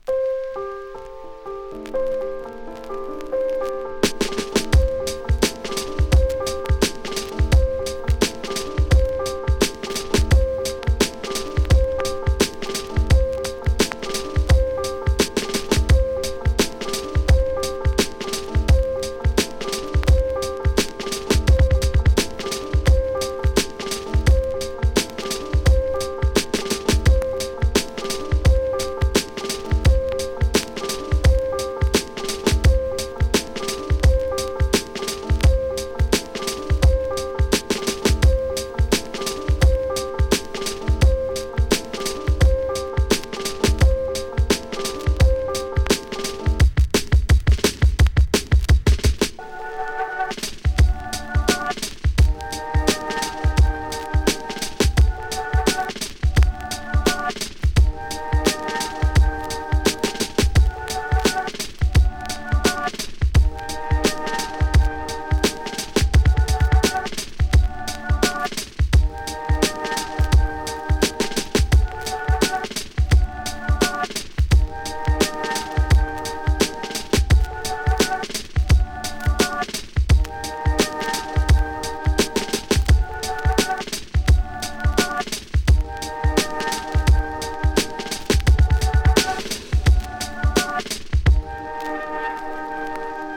ビート、サンプリングセンス、「間」など、聴きどころ多数のアルバム。